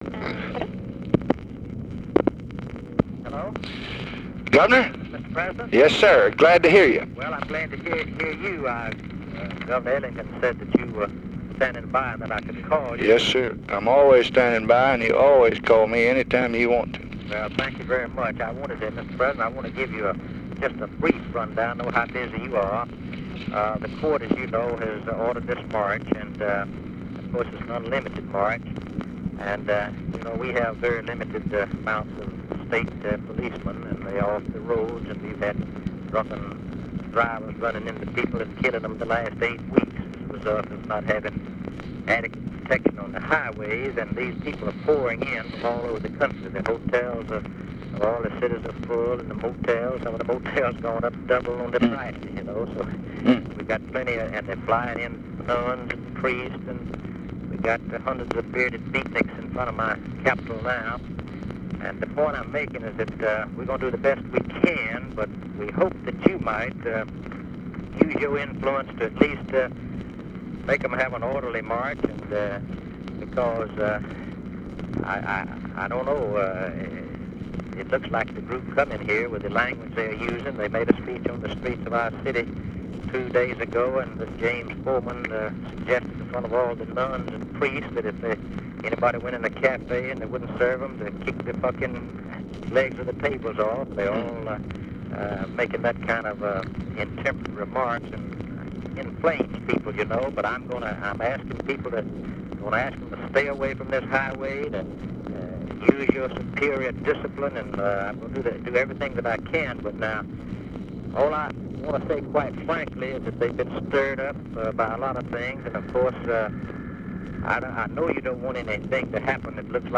Conversation with GEORGE WALLACE, March 18, 1965
Secret White House Tapes